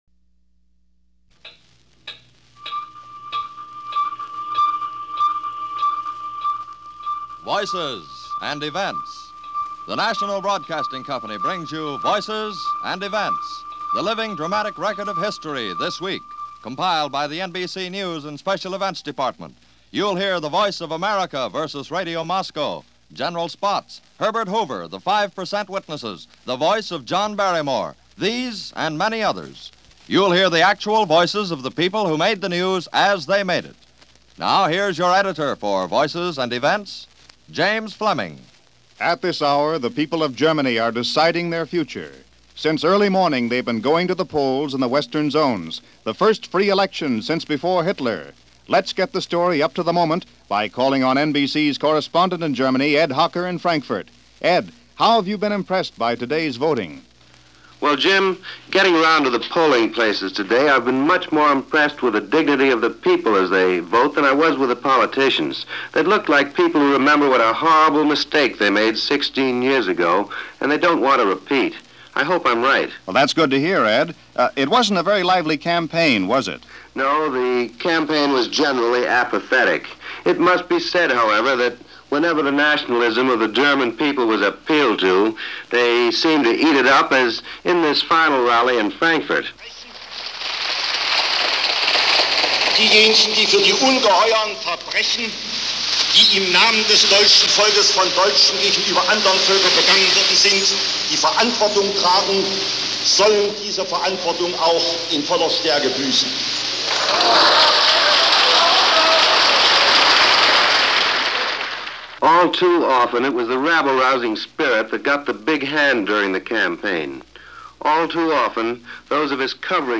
But generally, it was an August 14th pretty unremarkable in 1949 – a lot of names and places covered in this broadcast of Voices and Events.